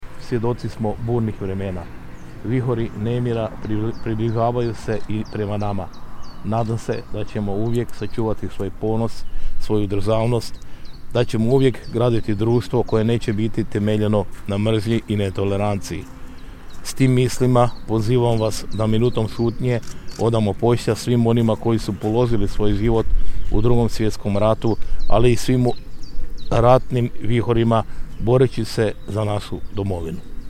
Oslobođenje Međimurja od nacifašističke okupacije, Prelog, 4.4.2022.
Gradonačelnik Ljubomir Kolarek u svom je govoru istaknuo: